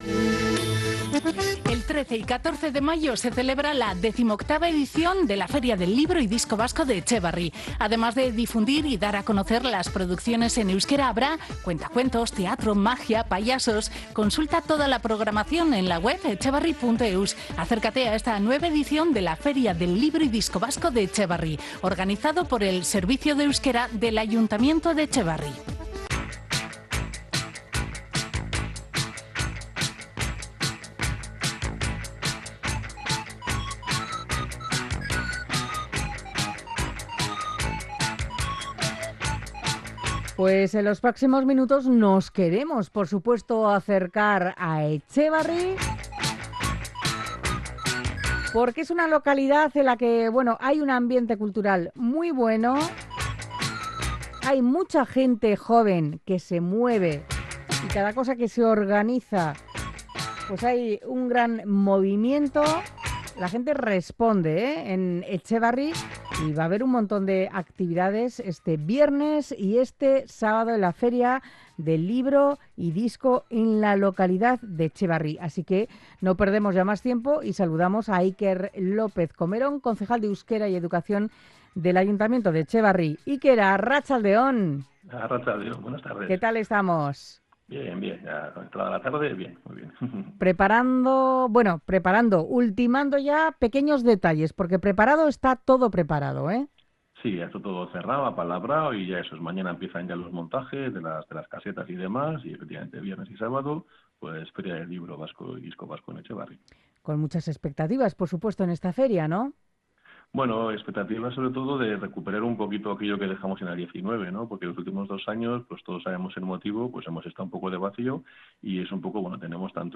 Iker López Comerón, concejal de euskera y educación del Ayuntamiento de Etxebarri habla en Onda Vasca de la nueva edición de la feria del disco y libro este jueves y viernes en la plaza del ayuntamiento con descuentos del 20% para todos los etxebarritarrak y de las muestras teatrales e infantiles que amenizarán la localidad.